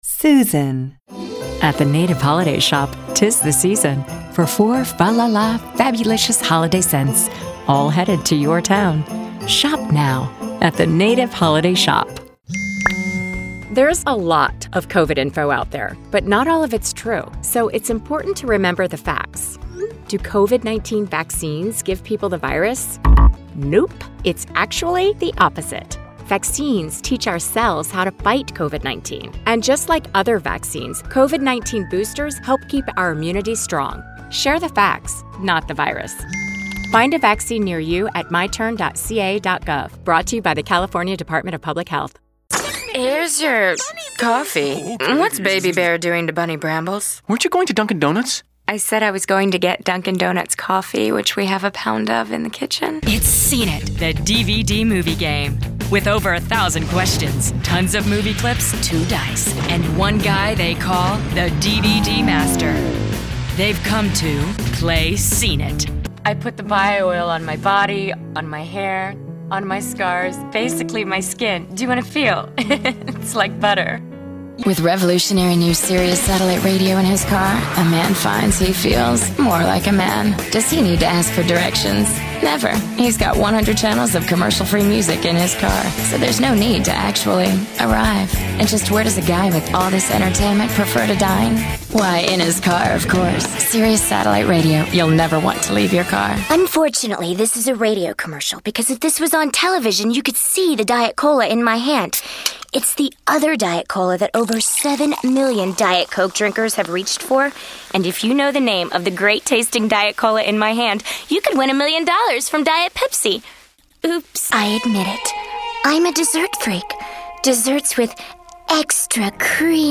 Showcase Demo
caring, compelling, concerned, genuine, smooth, spanish-showcase, thoughtful, warm
announcer, caring, classy, confident, informative, professional, spanish-showcase, upbeat, warm